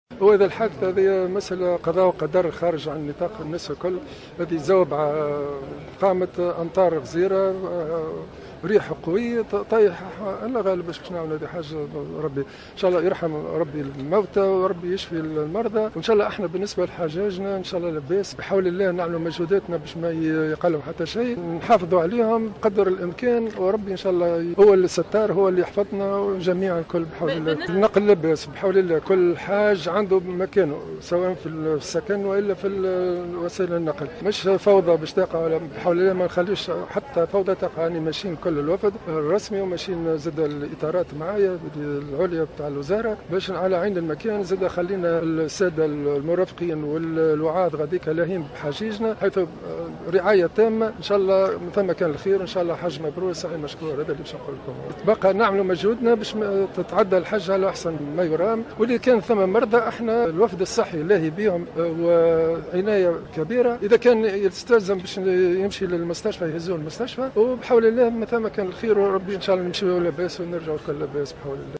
وقال وزير الشؤون الدينية عثمان بطيخ في تصريح للجوهرة أف أم خلال ندوة صحفية عقدت اليوم في مطار الحجيج بمناسبة سفر الوفد ان الوزارة ستبذل قصار جهدها لرعاية حجيجنا الميامين مبينا أن الوفد الرسمي سيتولى مرافقة الحجاج ورعايتهم مضيفا أن الاطار الطبي المرافق لهم مجند لانجاح الحج هذه السنة.